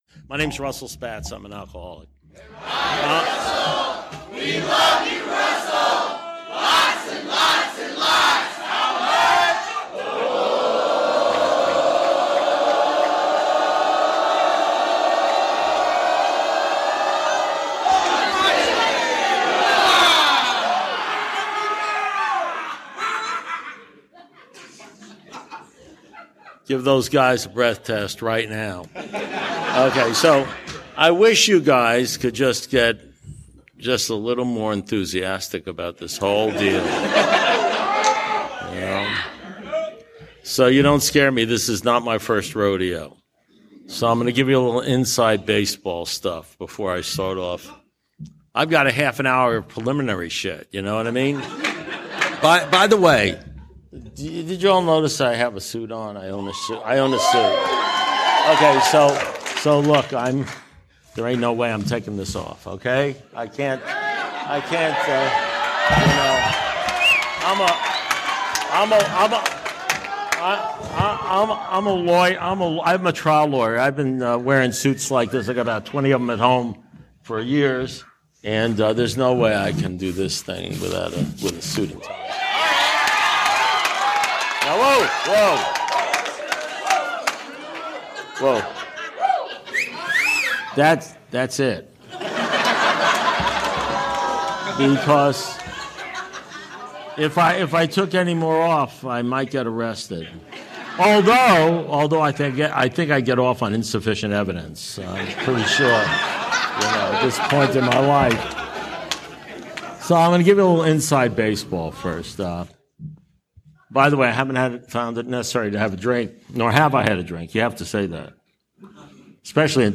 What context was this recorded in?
Heartland Area Conference of Young People in AA, Wisconsin Dells, April 7, 2023